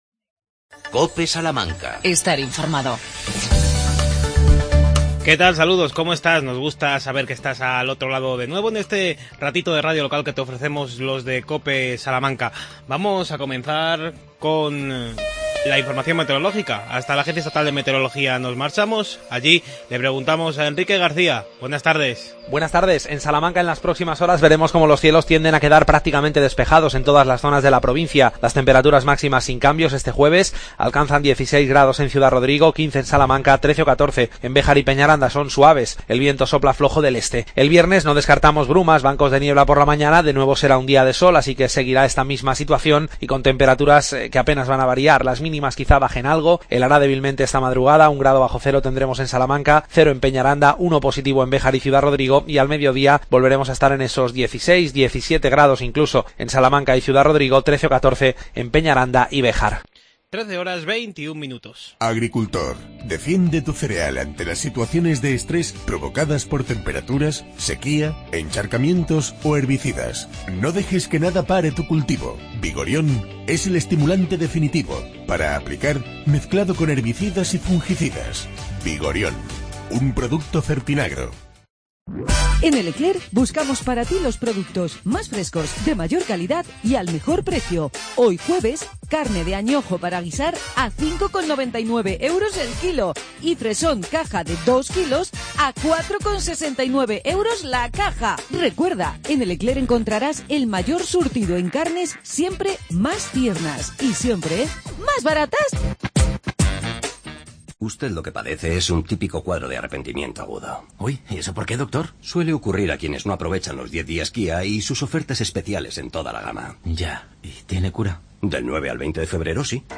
Consultorio